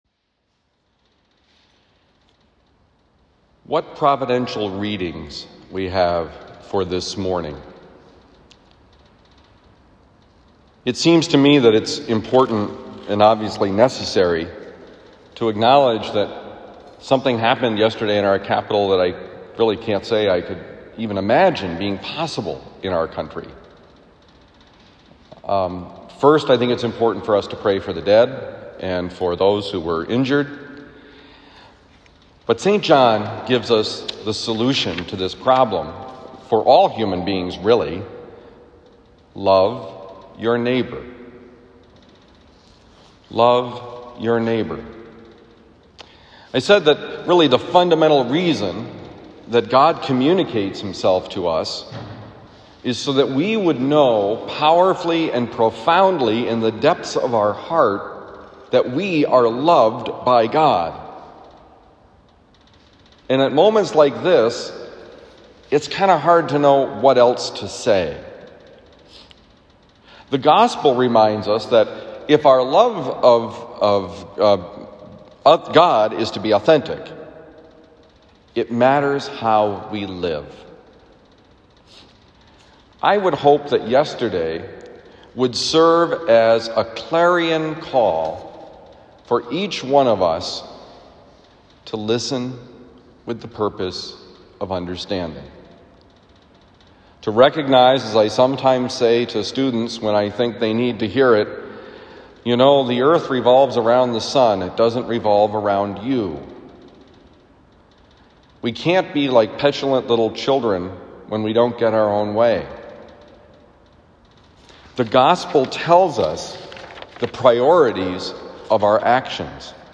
Homily for January 7, 2021
Given at Christian Brothers College High School, Town and Country, Missouri